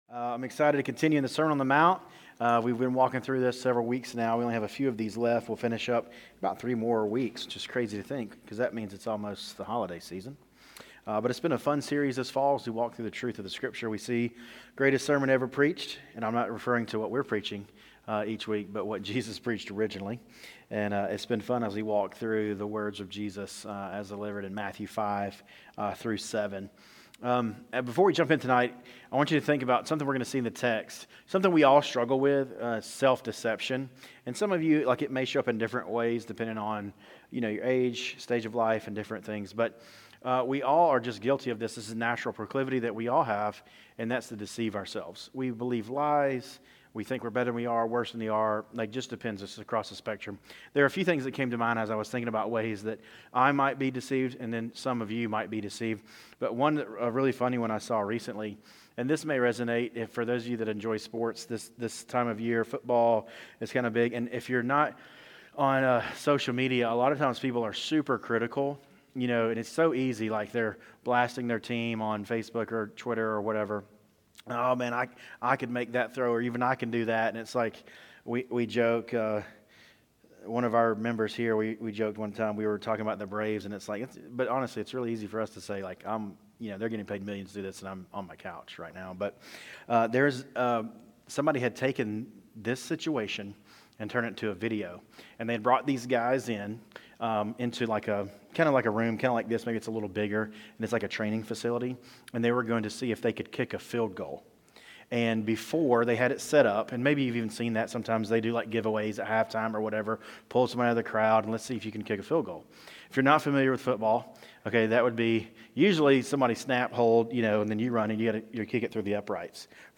City View Church - Sermons